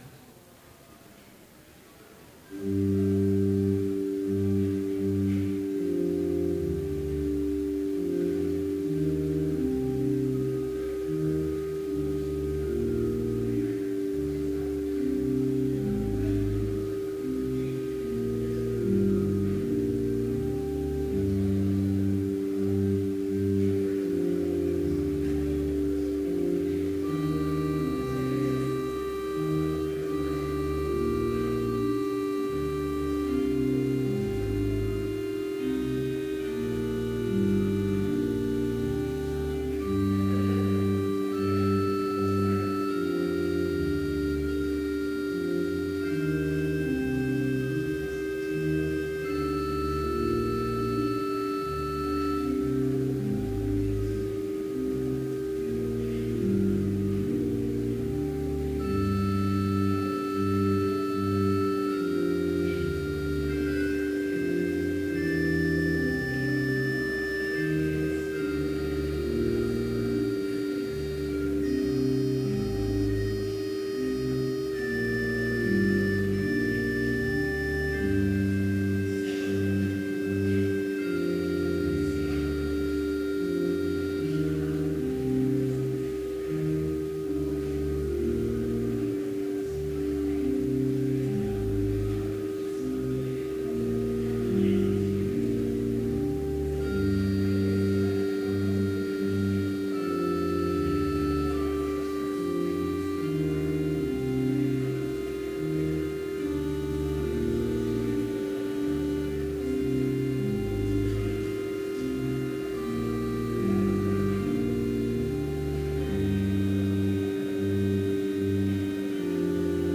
Complete service audio for Chapel - April 6, 2017